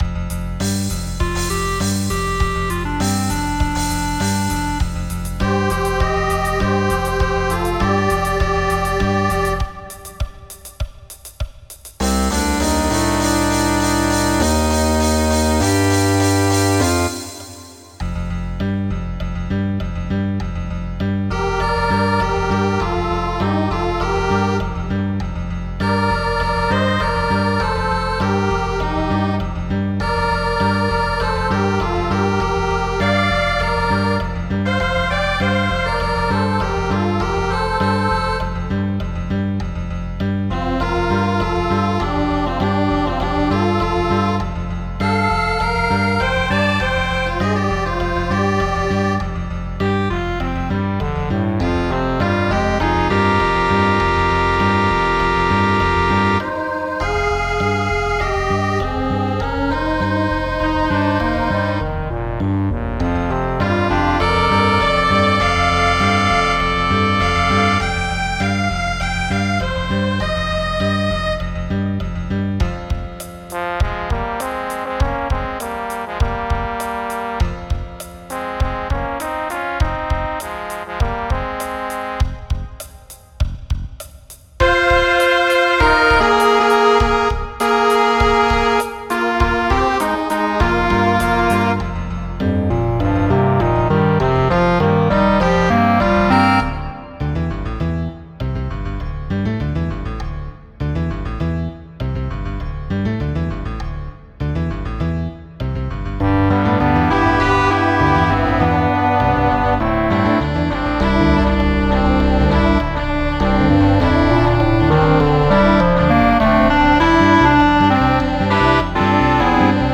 TribalButt-midi.m4a